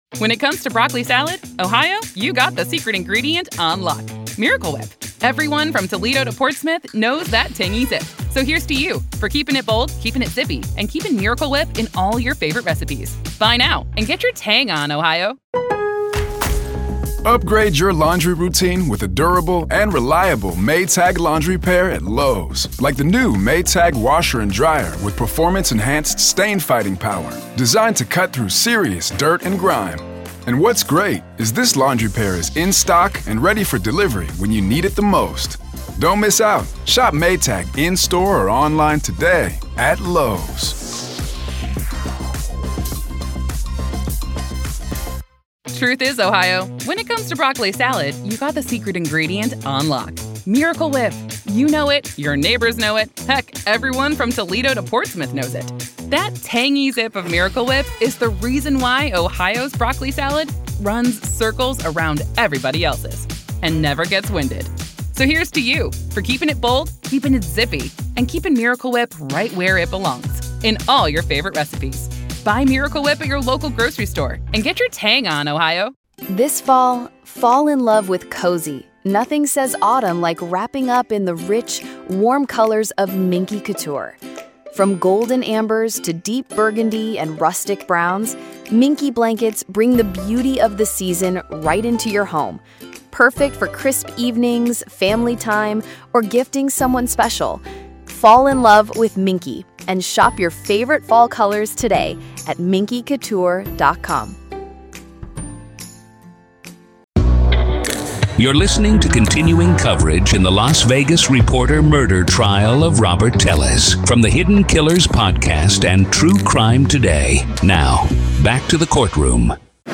Court Audio-NEVADA v. Robert Telles DAY 5 Part 1